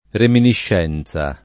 vai all'elenco alfabetico delle voci ingrandisci il carattere 100% rimpicciolisci il carattere stampa invia tramite posta elettronica codividi su Facebook reminiscenza [ reminišš $ n Z a ] s. f. — non reminescenza né riminiscenza